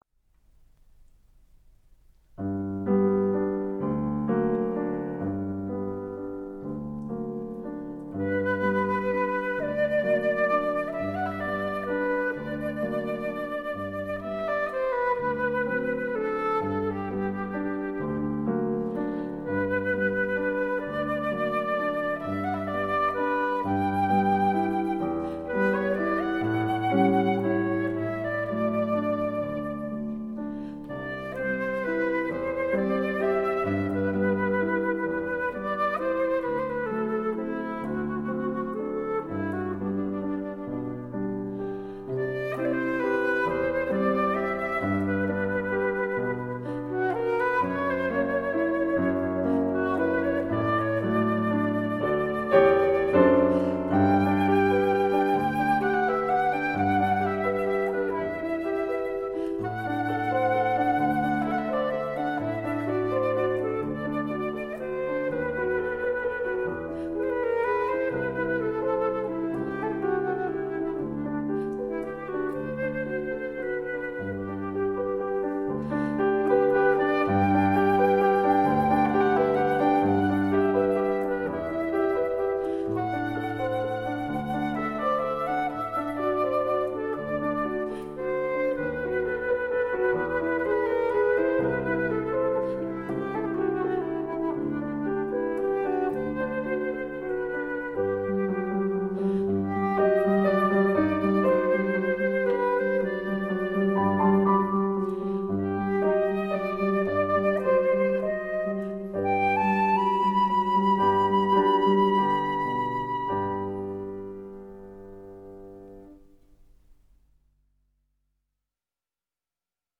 小品式的音乐，长笛、钢琴和竖琴的组合
录音的音色也是赏心悦耳
它的音色柔美，金属光泽中透出一种人性化的特质
这三件乐器的录音效果则非比寻常，声部的平衡感和音质的透彻感绝可媲美世界同类制作。